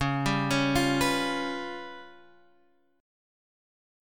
Db7b5 chord